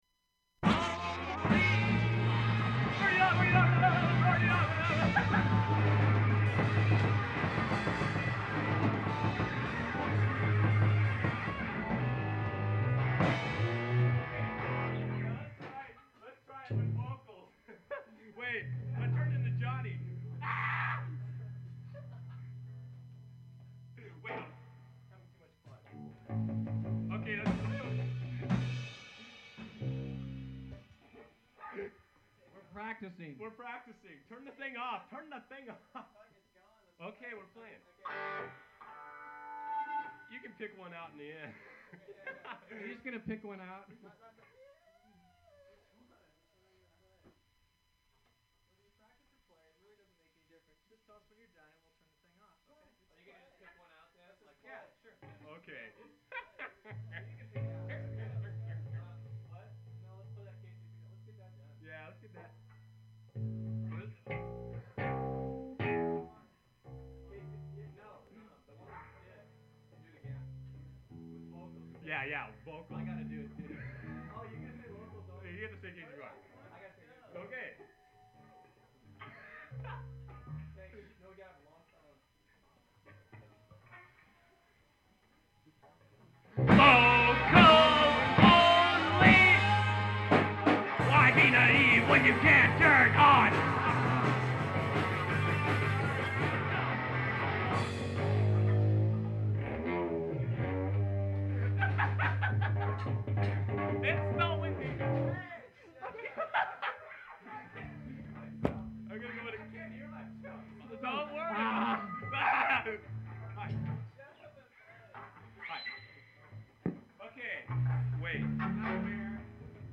punk, attempt 1
funk, attempt 1
Open reel audiotape